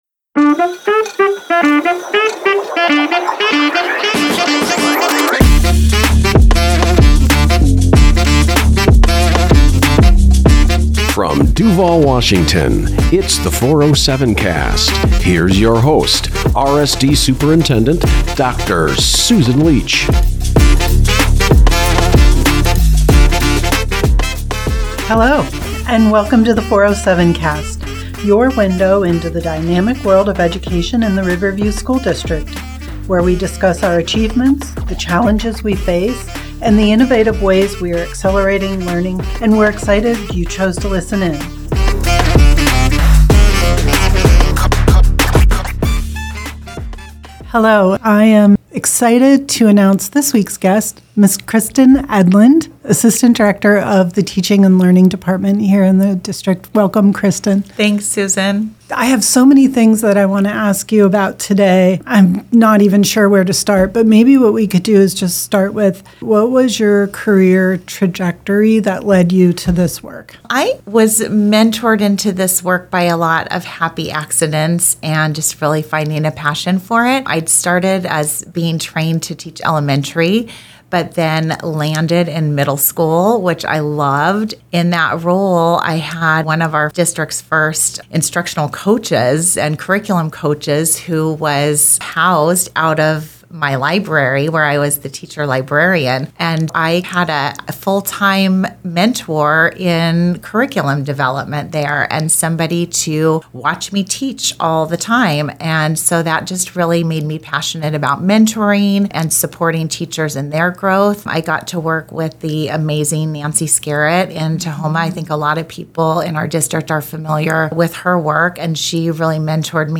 Curriculum adoption, what the district is piloting, curriculum rigor and the latest updates in professional development are all part of this interesting conversation.